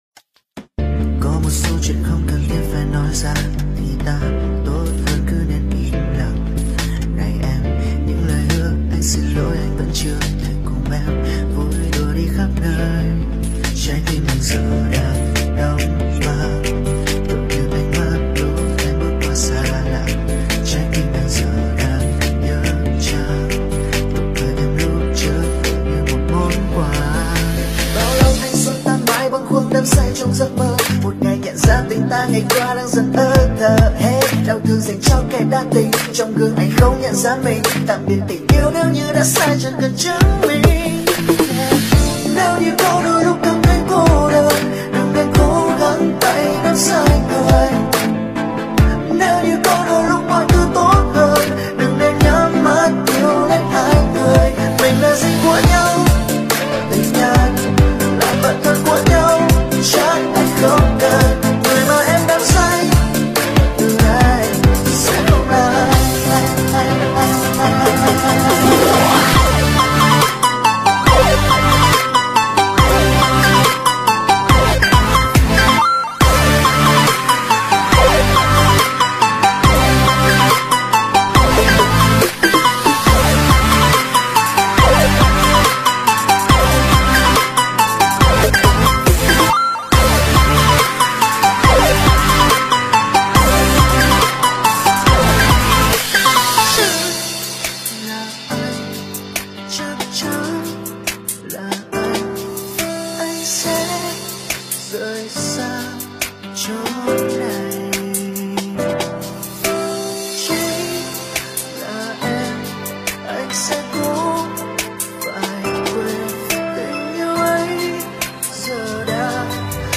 Nhạc EDM